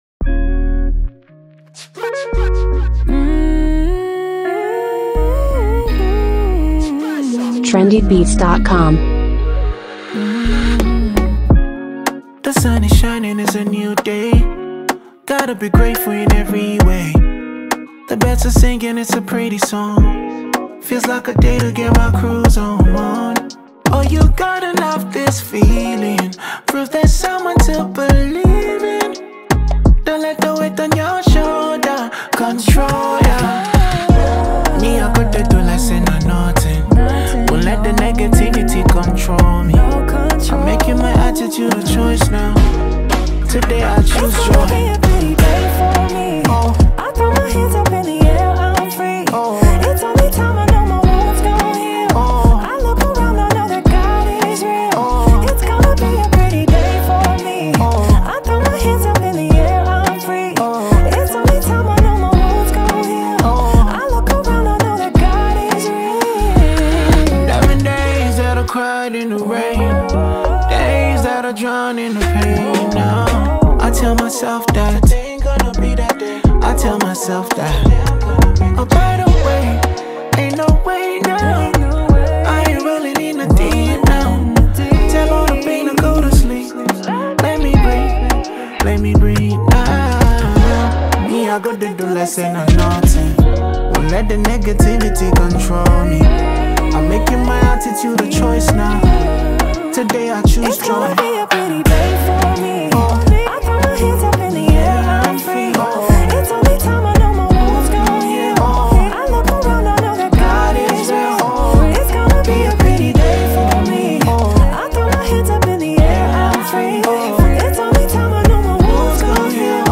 the top-notch afro-gospel singer